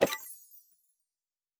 Special & Powerup (39).wav